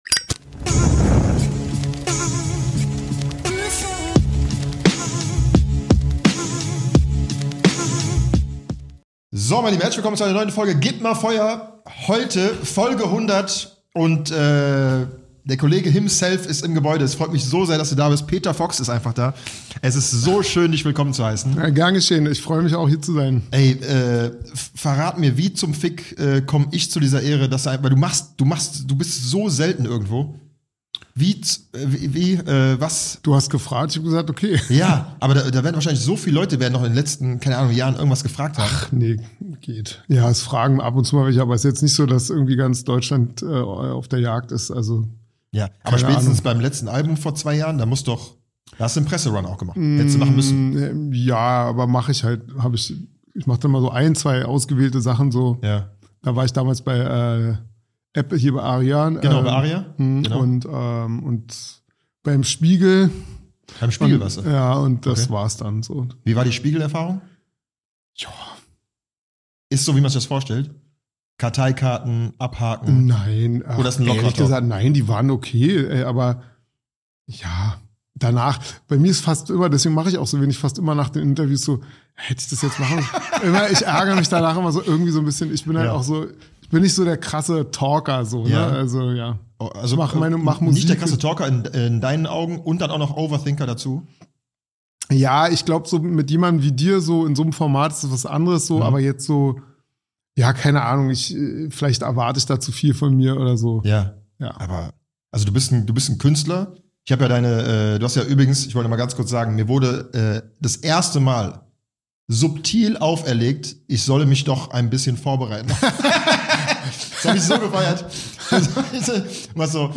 Peter Fox ist im Gebäude! Die Musiklegende hat sich die Ehre gegeben und es ist ein nicer Talk entstanden von der ereignisreichen Vergangenheit bis zur erfolgreichen Gegenwart.